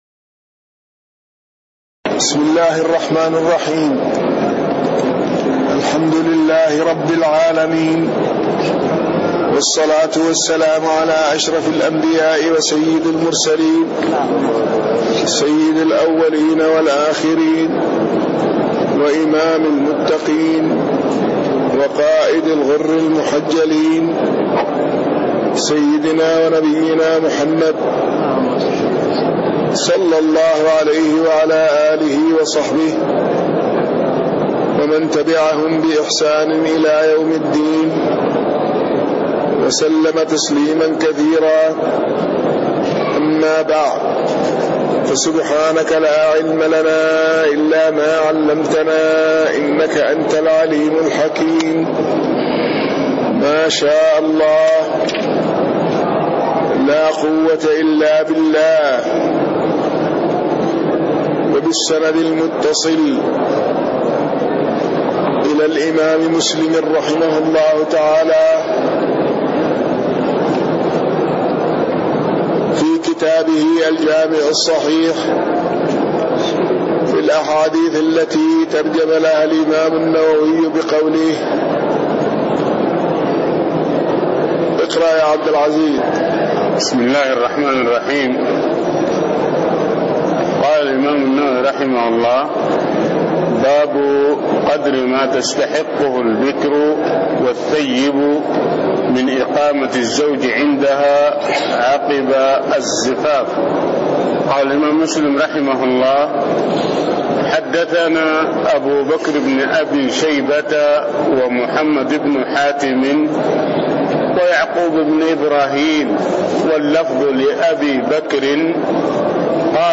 تاريخ النشر ٣٠ رجب ١٤٣٤ هـ المكان: المسجد النبوي الشيخ